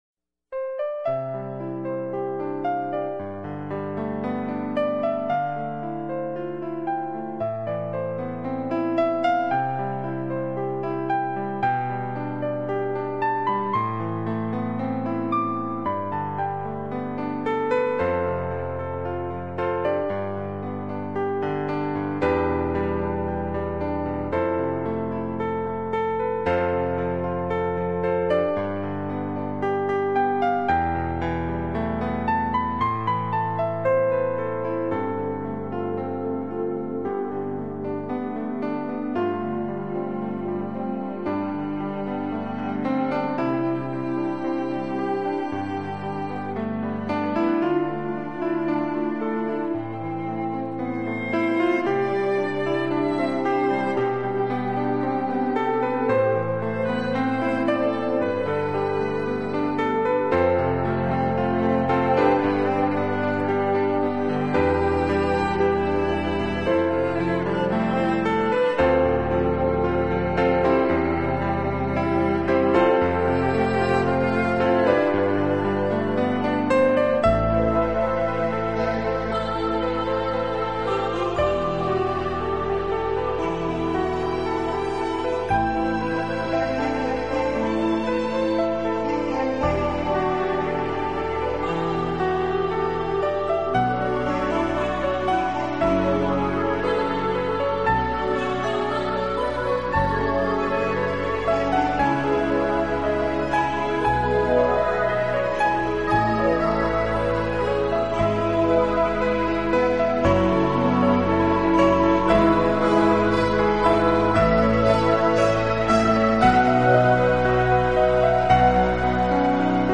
音乐类型：New Age
巧与情绪，使她的音乐具有一种纯净、轻盈、典雅、脱俗的美和想象空间。